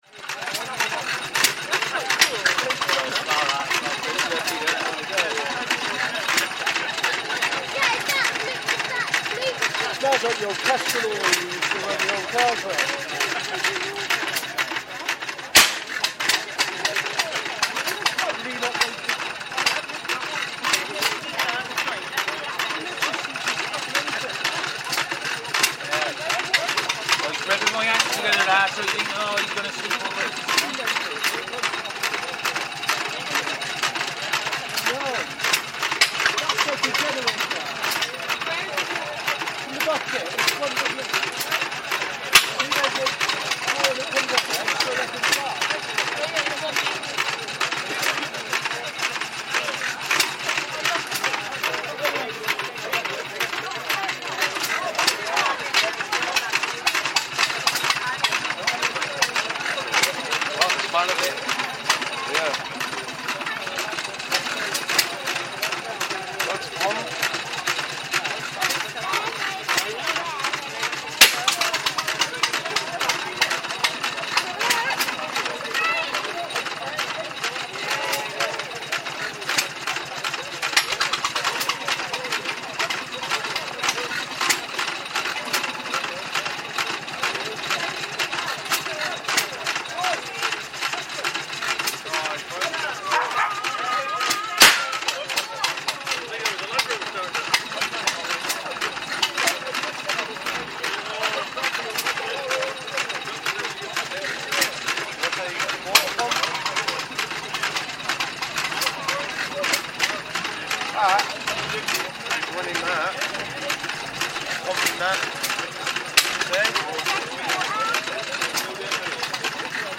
Stationary steam engines
Stationary steam Eegines at the Coleford Festival of Transport. The stationary steam engines at the Coleford Festival of Transport always attract attention with their mechanical symphony of rhythmic chugging, the high pitched hiss of steam, and the clanking of rods and flywheels. The sounds of this engine were particularly beguiling. It sometimes faltered, and for a moment it seemed like it would stop and die, only to start up again with renewed vigour.